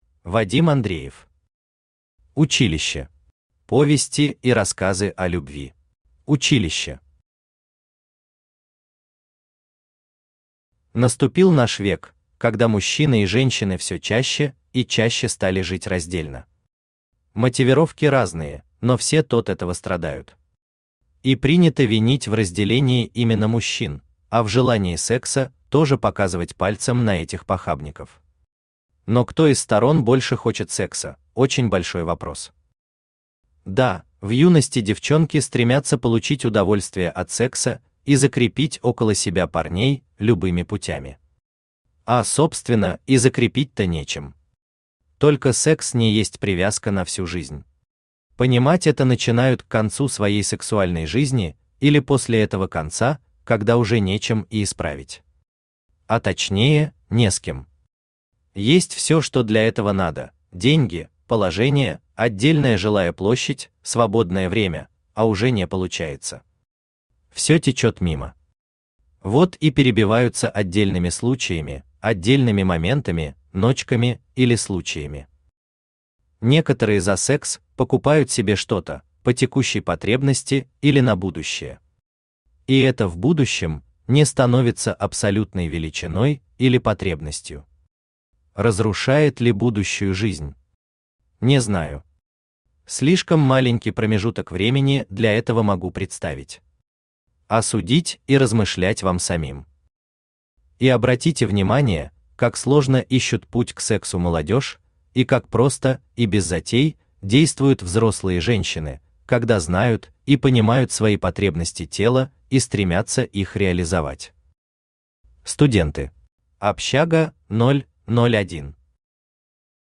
Аудиокнига Училище. Повести и рассказы о любви | Библиотека аудиокниг
Повести и рассказы о любви Автор Вадим Андреев Читает аудиокнигу Авточтец ЛитРес.